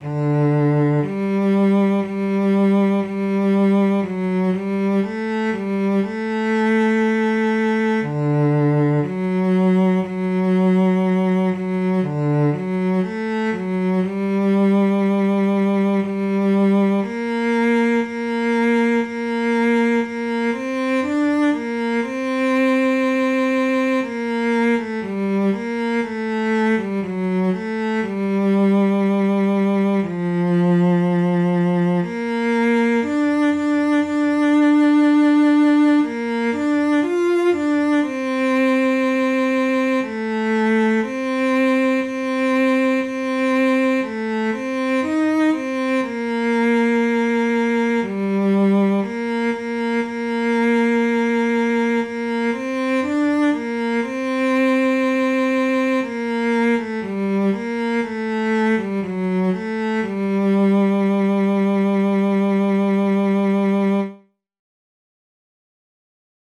G minor ♩= 60 bpm